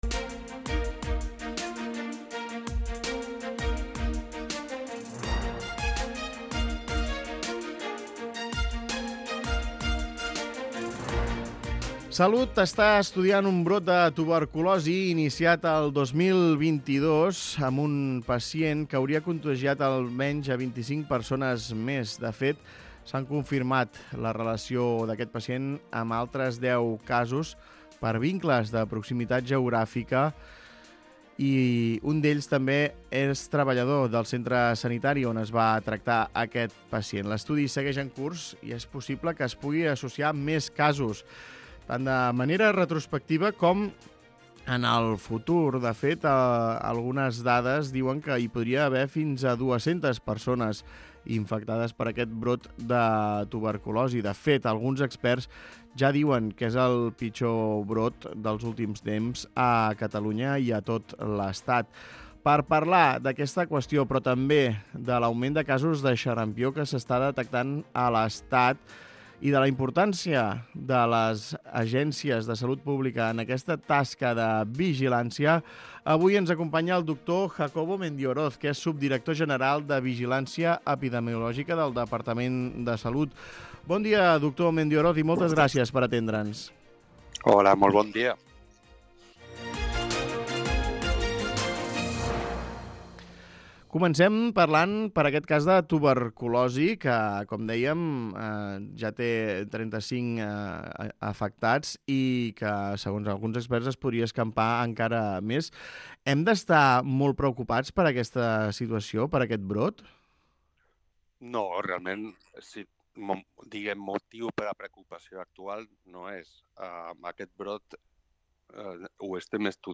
Entrevista amb Jacobo Mendioroz, subdirector general de Vigilància Epidemiològica i Resposta a Emergències de Salut Pública